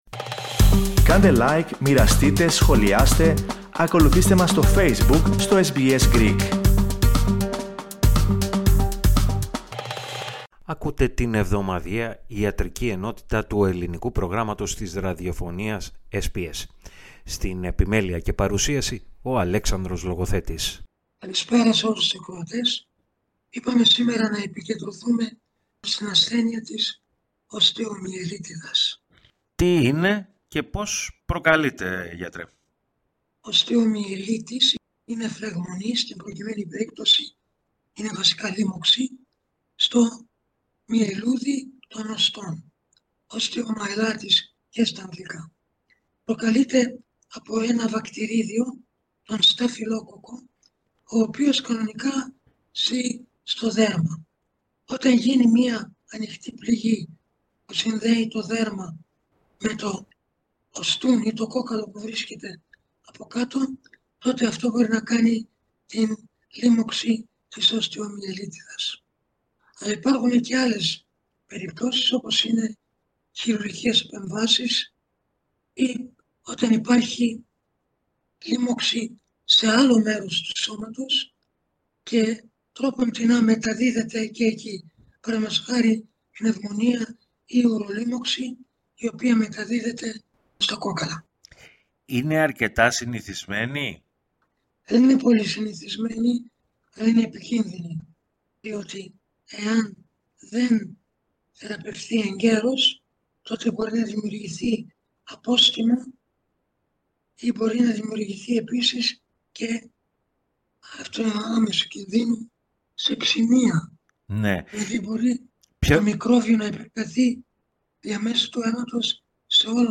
Ο γενικός γιατρός